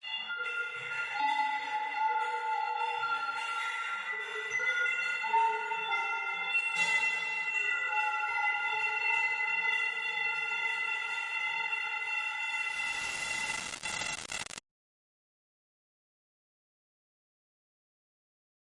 描述：这是一个怪异的声音。
标签： 吓人 爬行
声道立体声